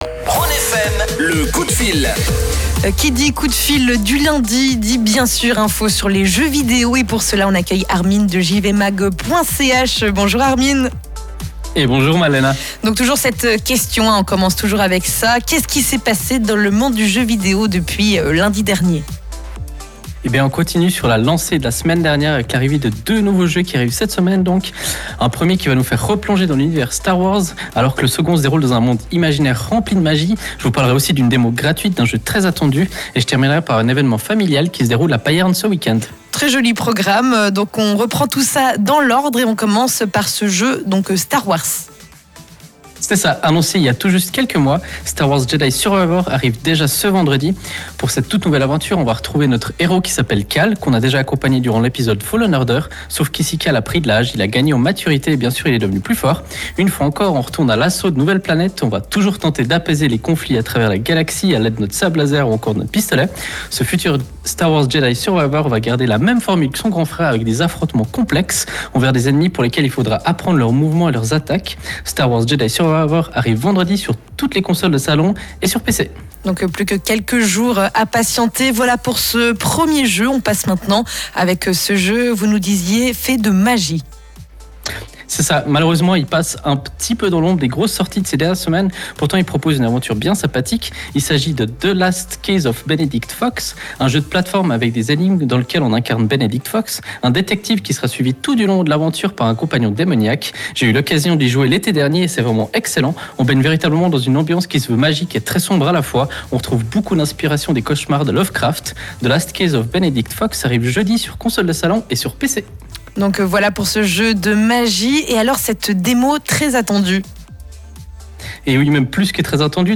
C’est lundi et comme tous les lundis, nous avons la chance de vous proposer notre chronique gaming sur la radio Rhône FM.